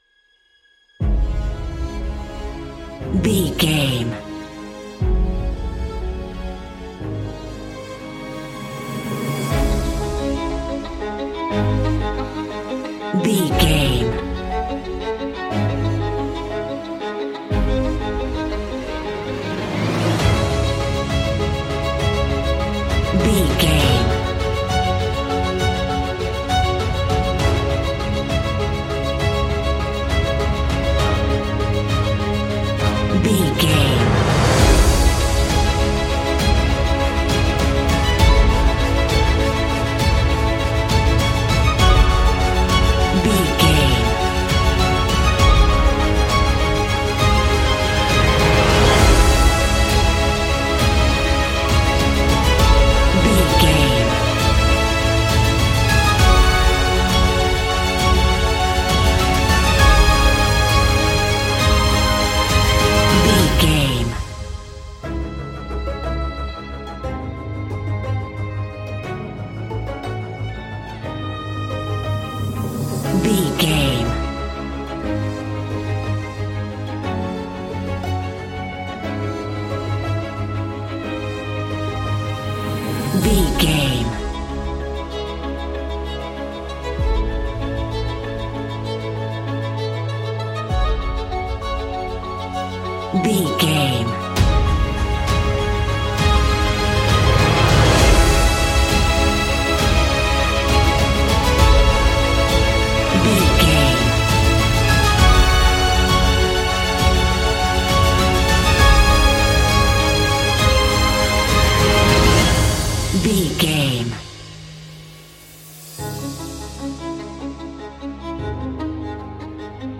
Orchestral track with melodic strings.
Epic / Action
Fast paced
In-crescendo
Uplifting
Ionian/Major
Fast
trumpet
strings
piano
brass
flutes
percussion
violin